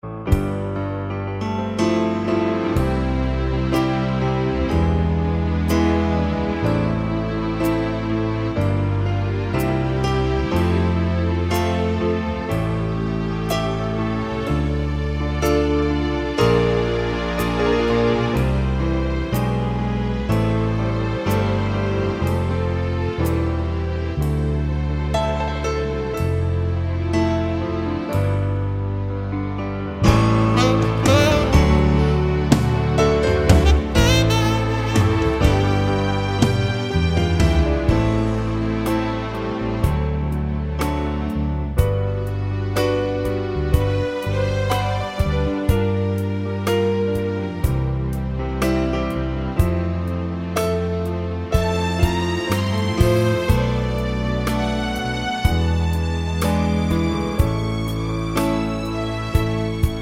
Minus Piano & Sax Solo Pop (1980s) 5:20 Buy £1.50